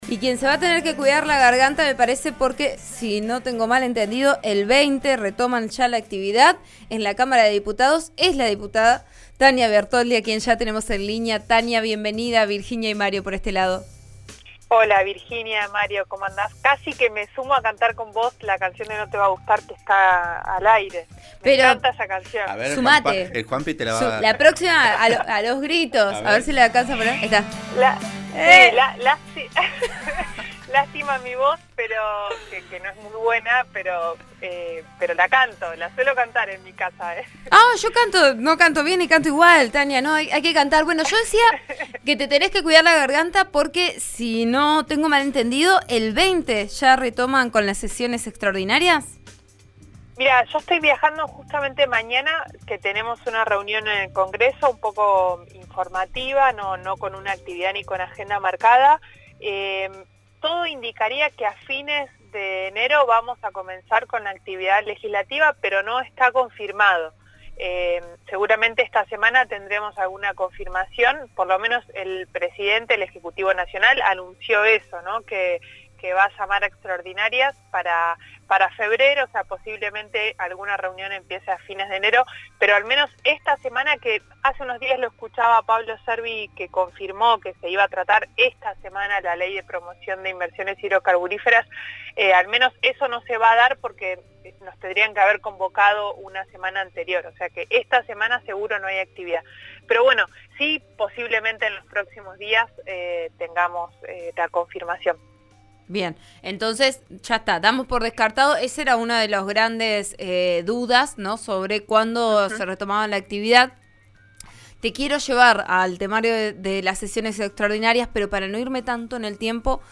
La diputada nacional por Neuquén Tanya Bertoldi lo acompañará y contó en declaraciones al programa Vos a Diario de RN RADIO (89.3) que comenzarán a trabajar en un proyecto para solucionar los «vacíos urbanos» de la ciudad de Neuquén.